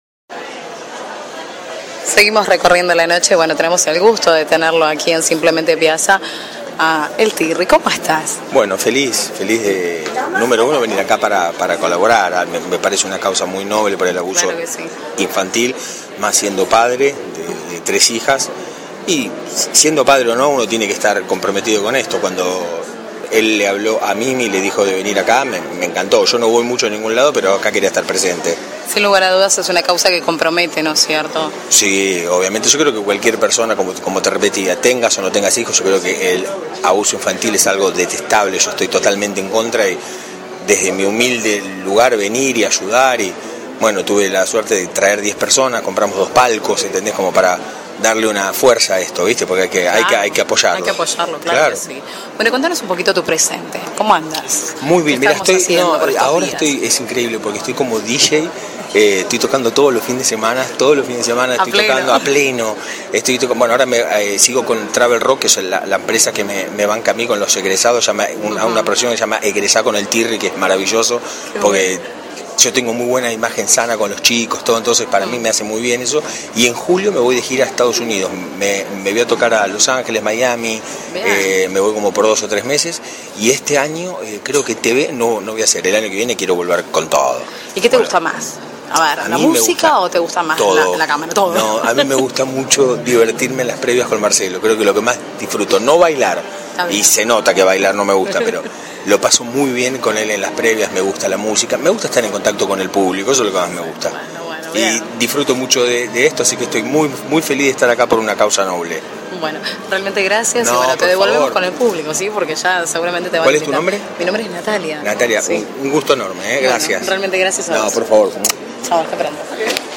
Roberto Piazza presentó en Señor Tango su nueva colección «Simplemente Piazza».
Entrevistas